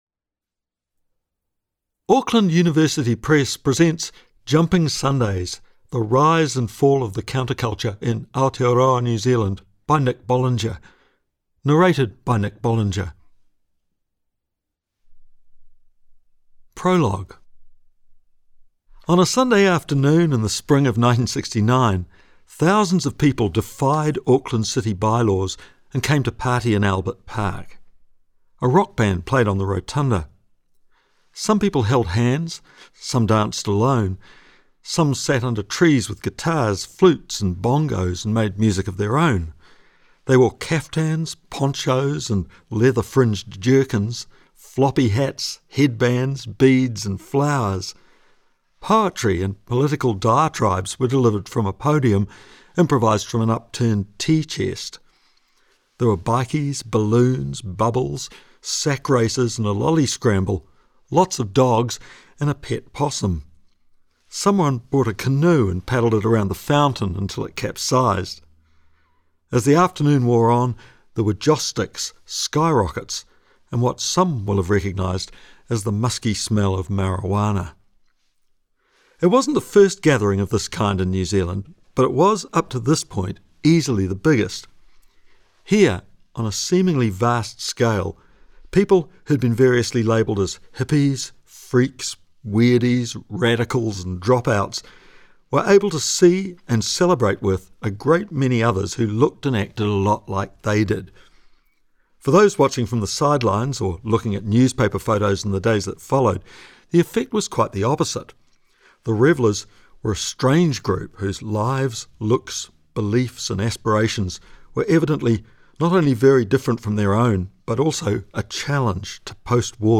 Format: Digital audiobook